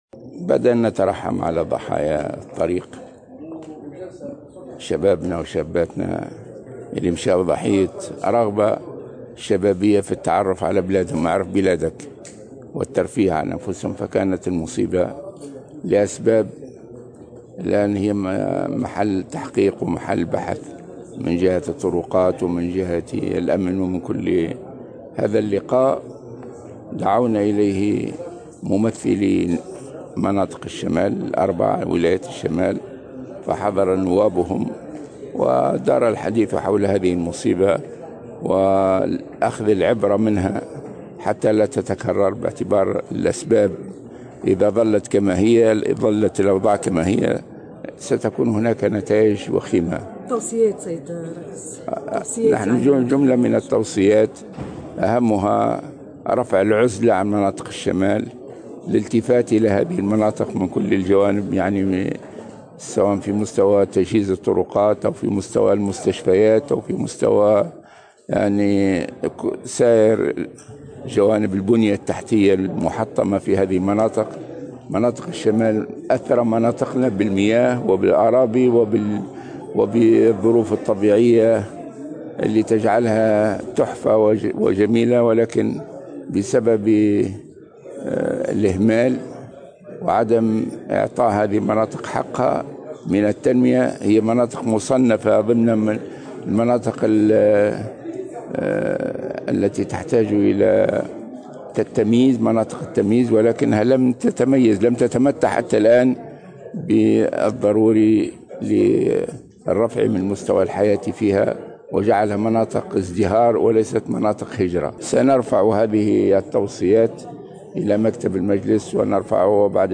قال رئيس مجلس نواب الشعب راشد الغنوشي في تصريح لمراسلة الجوهرة "اف ام" إن ضحايا فاجعة عمدون ذهبوا ضحية رغبة شبابية في التعرف على بلادهم والترفيه عن أنفسهم لأسباب مازالت محل تحقيق وبحث من جهة الطرقات و الأمن وغيرها وفق قوله.